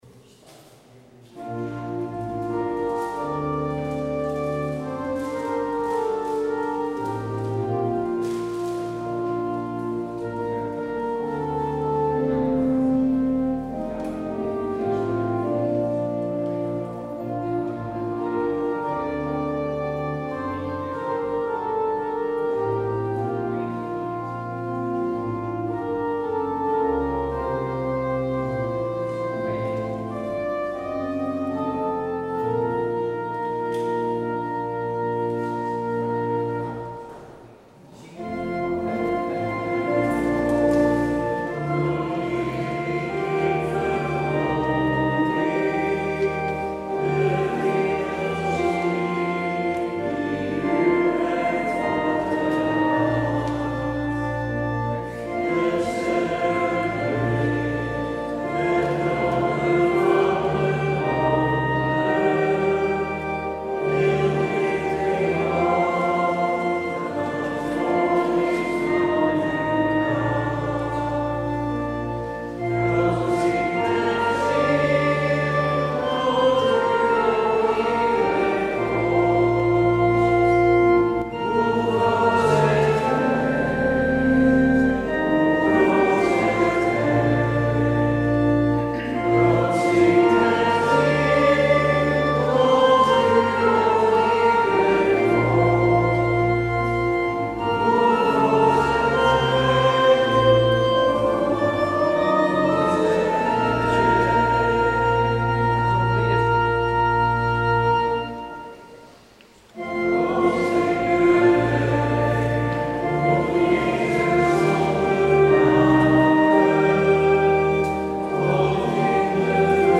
Lekenpreek Het thema van de meditatie is: ‘Waar vind je God buiten de kerk?’ n.a.v. The color purple & meer.
(standaard introductie lekenpreken) Het openingslied is: Heer ontferm u over ons. Het slotlied is: Neem de plaats de ruimte en de tijd.